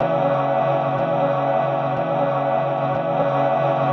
VoxStackLoop.wav